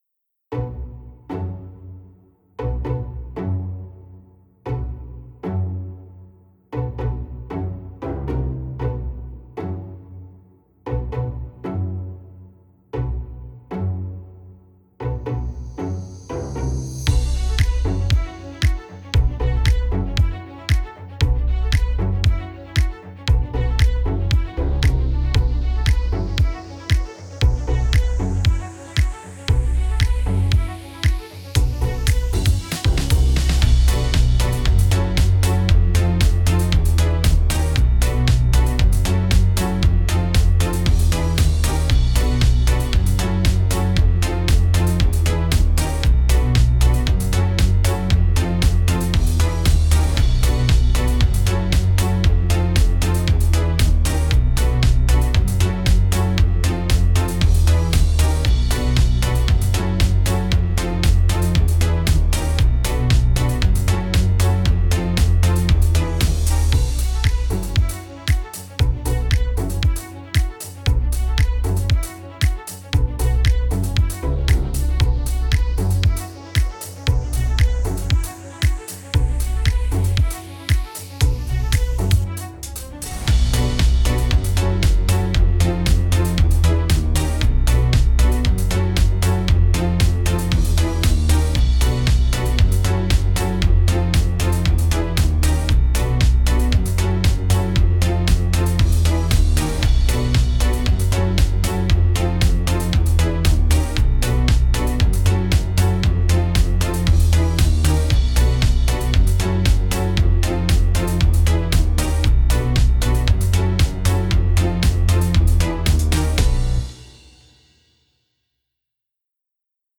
怖い・不穏
【ループ＆バリエーション版あり】ちょっぴりホラー？で不思議な雰囲気のBGMです◎
▼メロディなし版